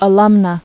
[a·lum·na]